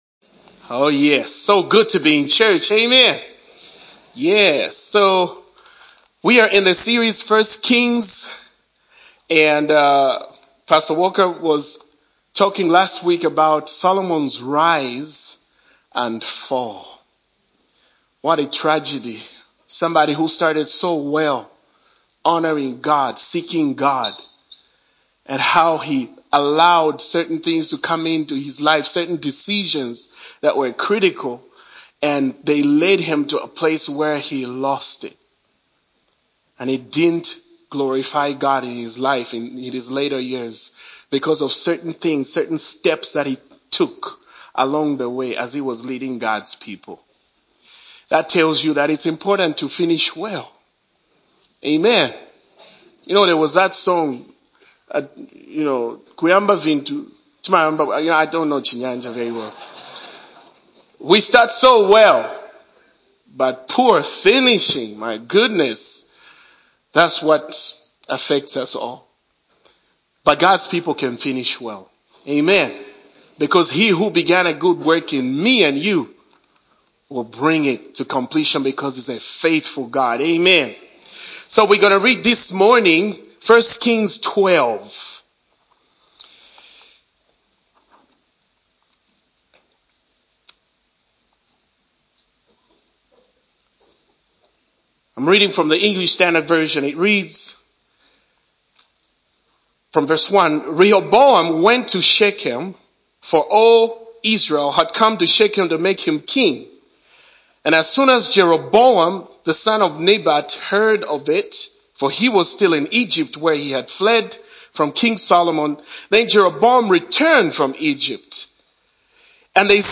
MLFC Sermons